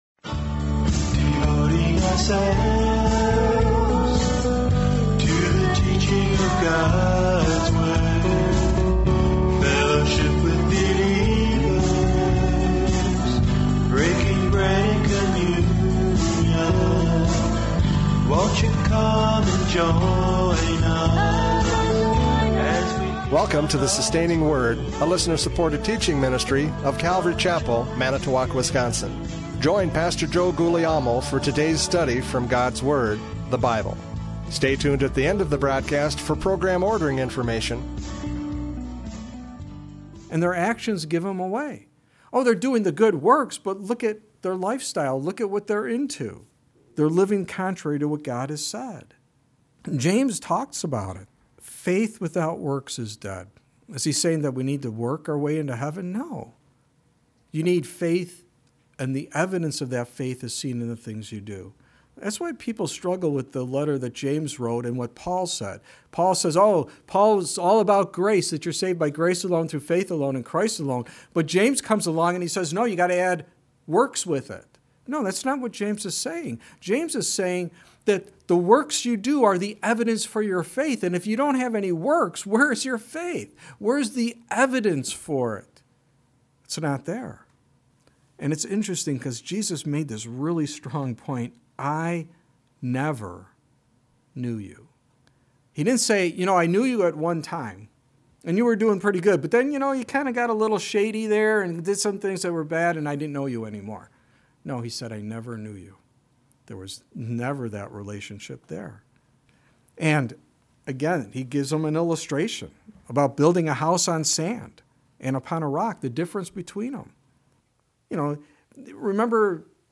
John 14:22-31 Service Type: Radio Programs « John 14:22-31 The Teacher!